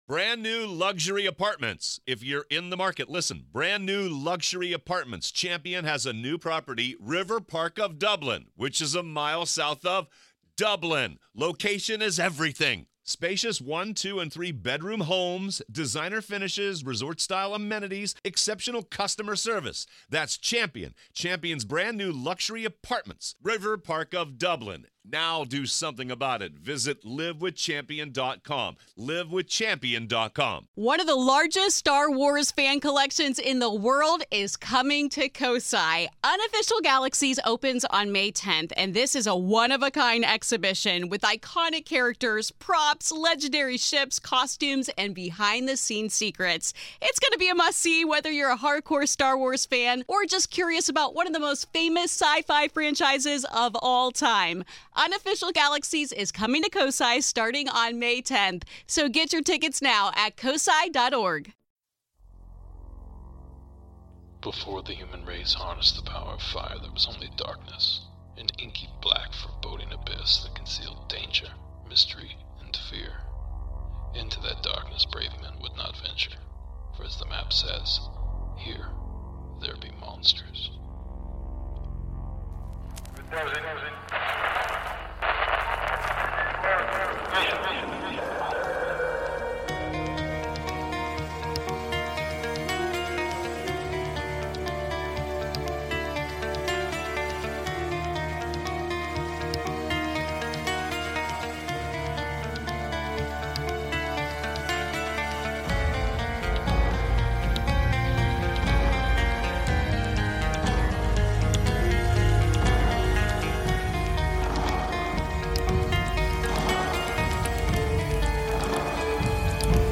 A collection of calls about mysterious encounters and missing time.